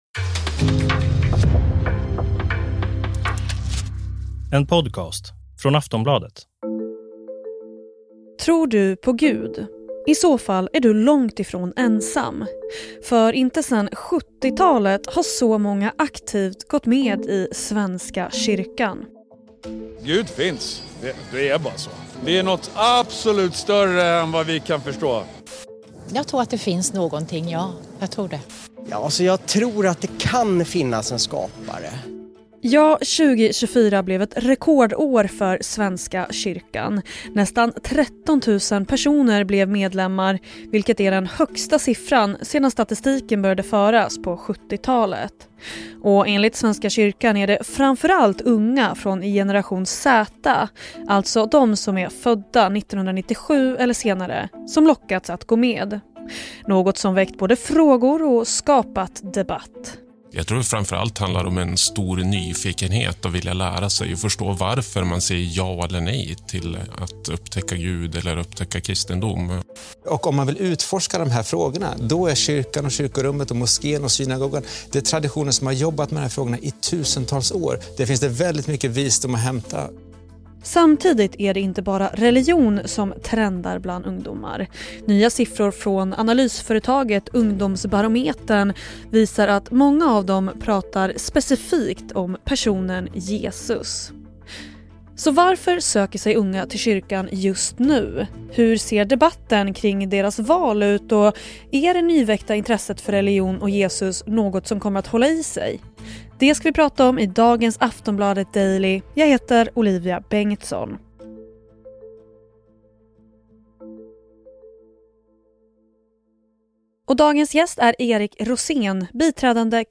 Klipp i avsnittet: SVT, Aktuellt, P4 Norrbotten.